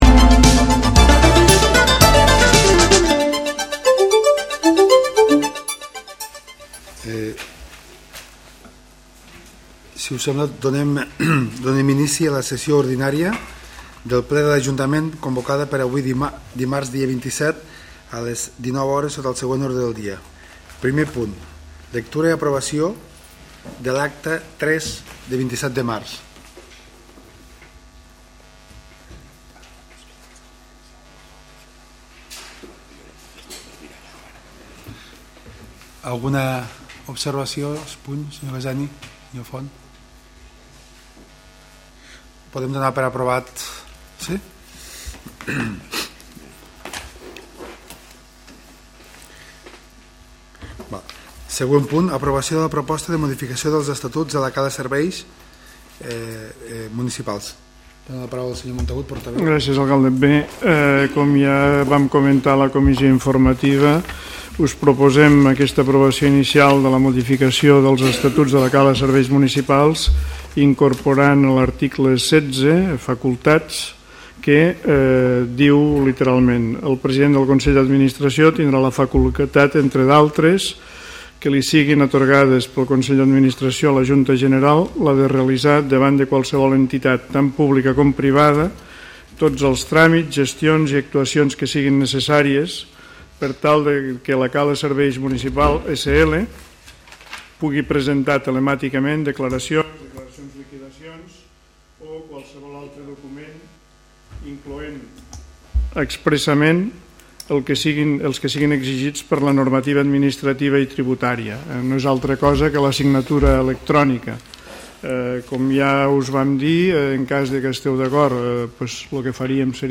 Ple de l'Ajuntament de l'Ametlla de Mar del 27 de maig de 2014.